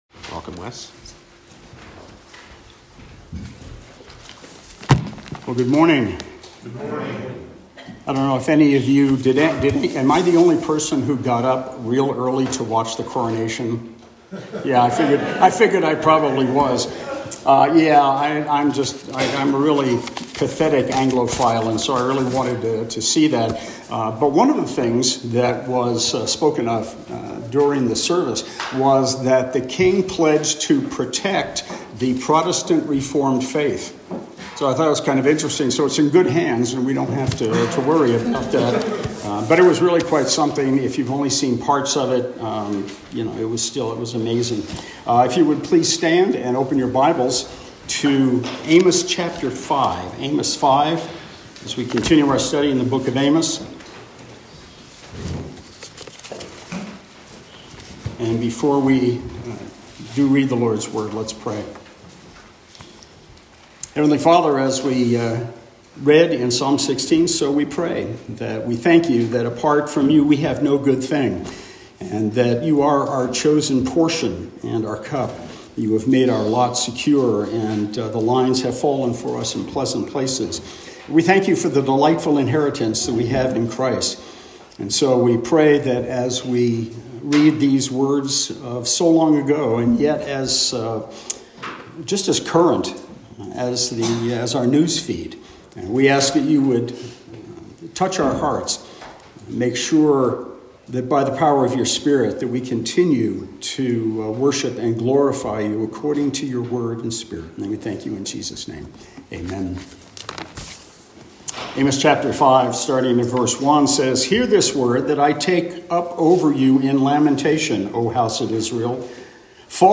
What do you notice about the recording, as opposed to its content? The Book of Amos Passage: Amos 5:1-17 Service Type: Morning Service « Interpreting Short and Troubled Lives Where Is the Evidence?